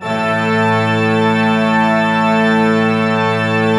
Index of /90_sSampleCDs/Propeller Island - Cathedral Organ/Partition F/PED.V.WERK M